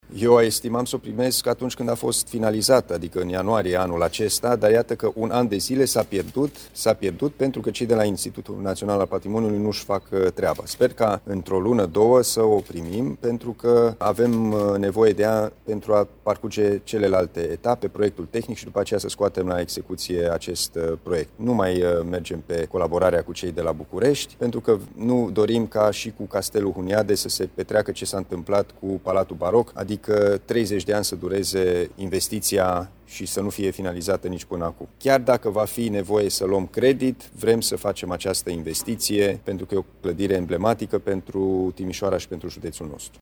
Reabilitarea monumentului închis de aproximativ 15 ani bate pasul pe loc, pentru că nici până acum Institutul Național al Patrimoniuui nu a predat administrației județene documentația DALI, mai spune Alin Nica.